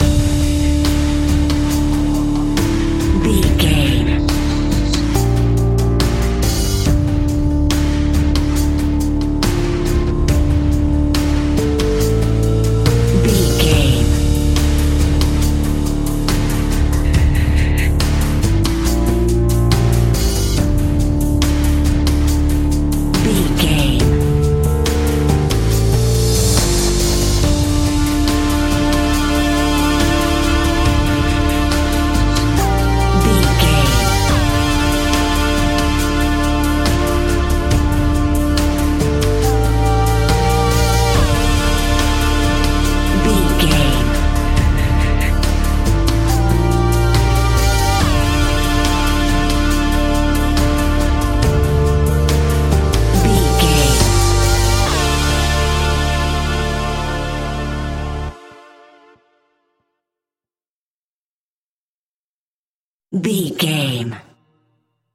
Aeolian/Minor
D
ominous
haunting
eerie
synthesiser
percussion
drums
suspenseful
electronic music